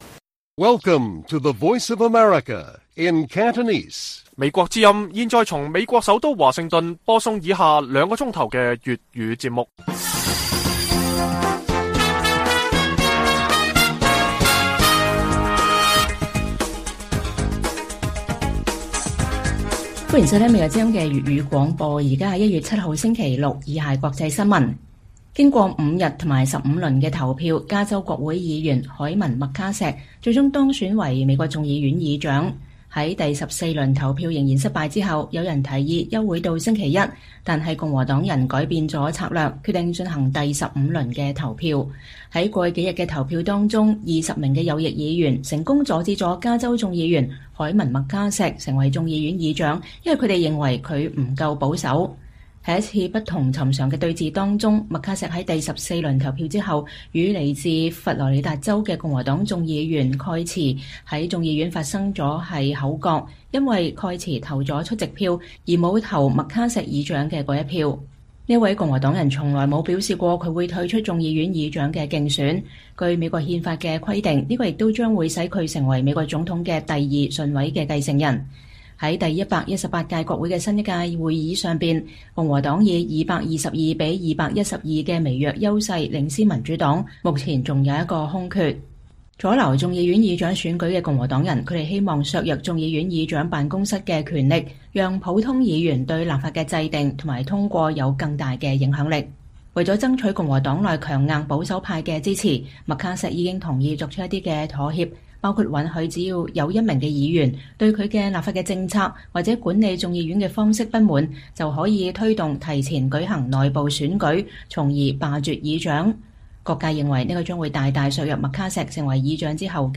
粵語新聞 晚上9-10點： 經過15輪投票後 加州議員麥卡錫當選眾議院議長